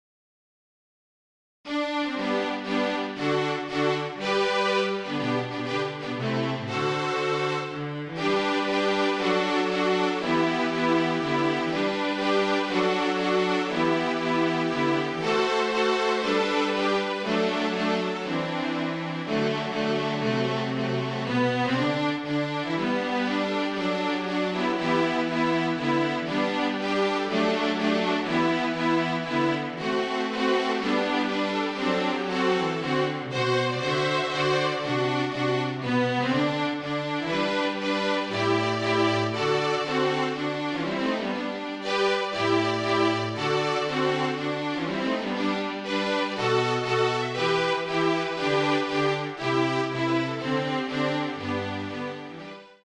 FLUTE TRIO
Flute, Violin and Cello (or Two Violins and Cello)